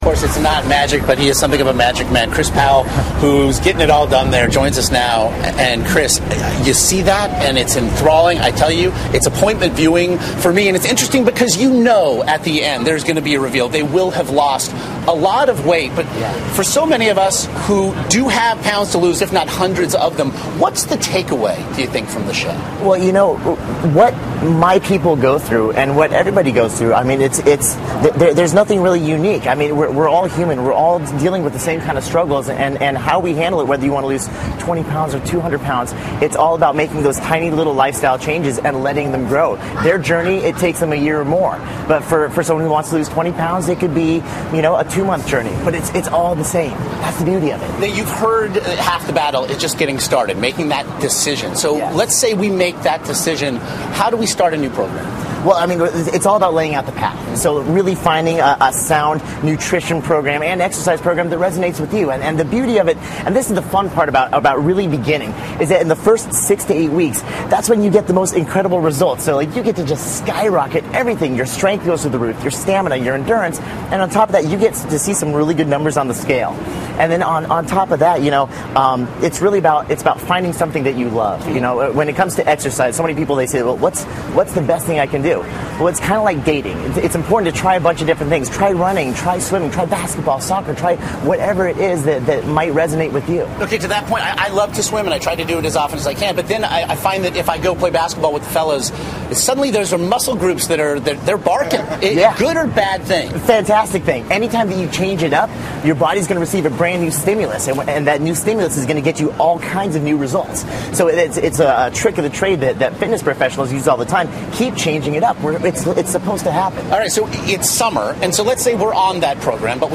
访谈录 2011-07-27&07-29 夏日减肥大揭秘 听力文件下载—在线英语听力室